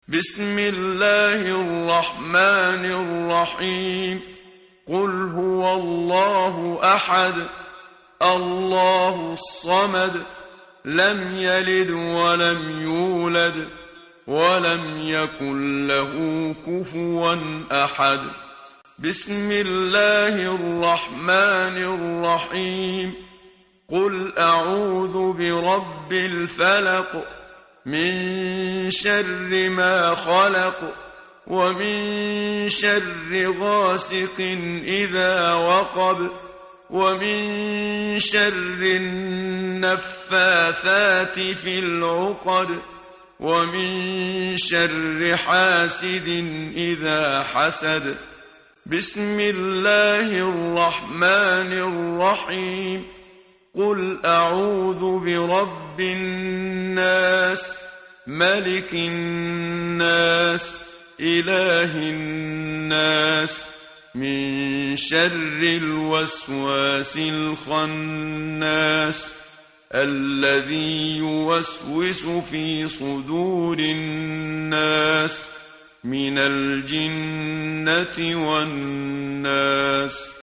tartil_menshavi_page_604.mp3